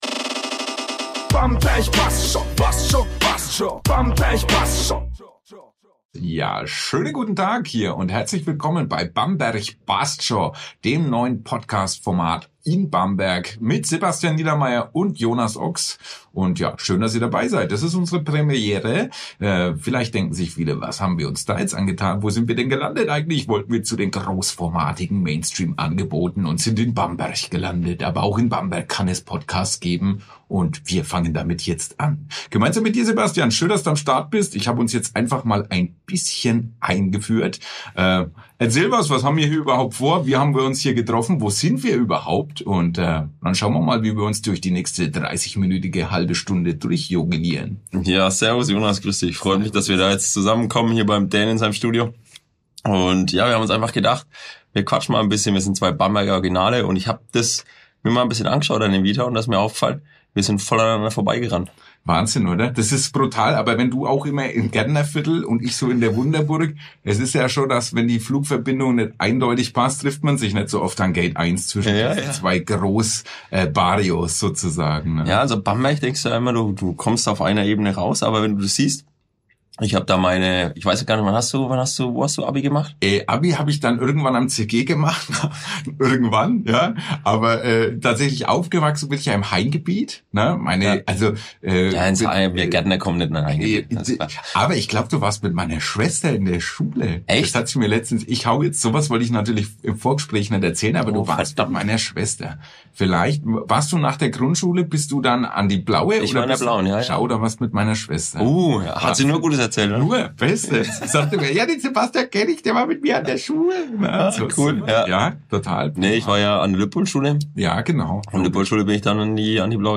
In der Vorpremiere von „Bamberg bassd scho!“ wird’s persönlich: Zwischen Gärtnerei und Radiostudio, fränkischer Sprache und Kindheitserinnerungen, Lieblingskneipen und Zivildienst plaudern die beiden über ihre Wurzeln – und verraten ihre Top 3 Orte für fränkisches Essen in Bamberg. Ein lockerer Auftakt mit Bier im Studio, ehrlichen Anekdoten und ganz viel Bamberger Lebensgefühl.